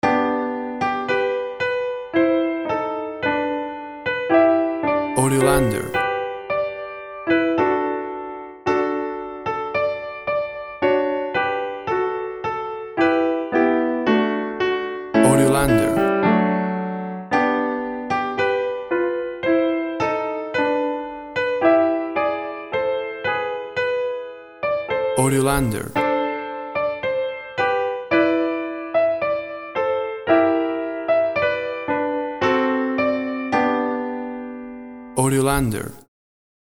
played on a Grand Piano
WAV Sample Rate 16-Bit Stereo, 44.1 kHz
Tempo (BPM) 110